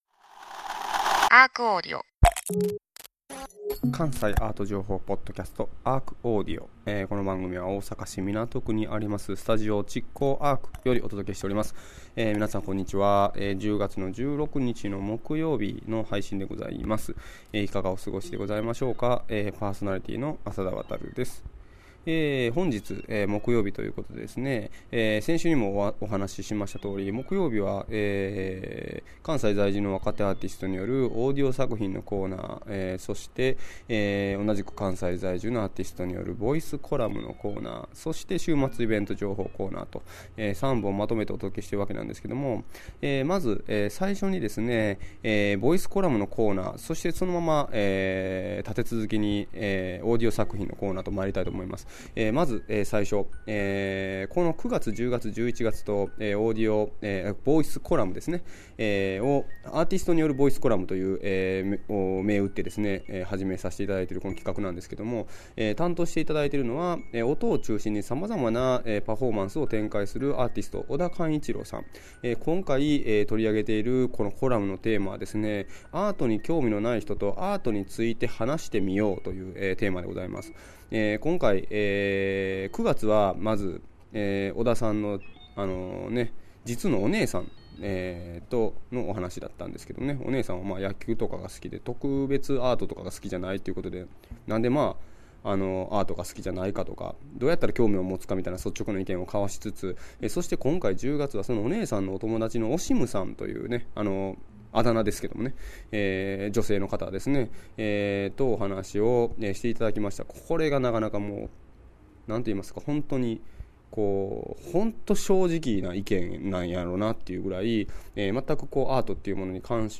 そしてオーディオ作品は、この夏、AppleStoreShinsaibashiにて開催された本番組の公開録音ライブより超脱力系ヒップホップギターデュオ：パーフェクトダンサーの登場！